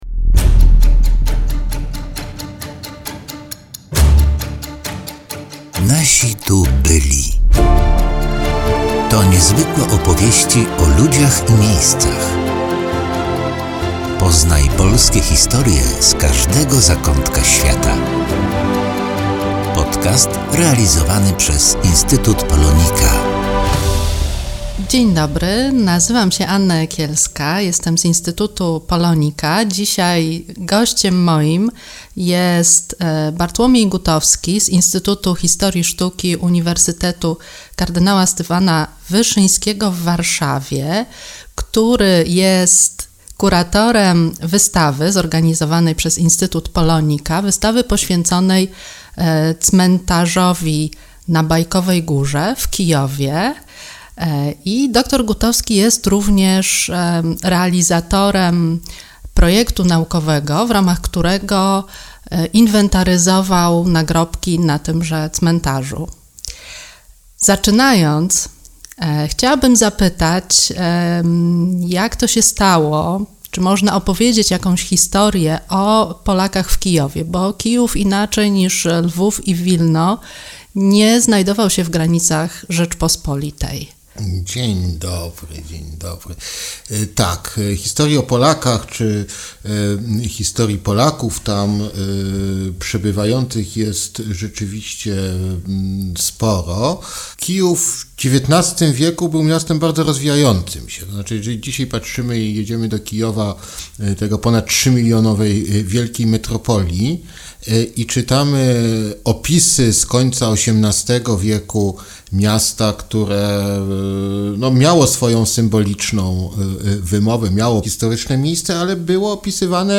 W drugim odcinku rozmawiamy z